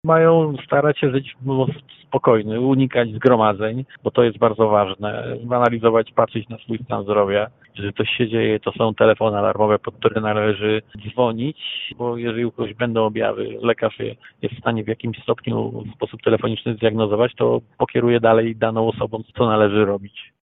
Prezydent Zielonej Góry apelował do mieszkańców miasta o spokój i ostrożność: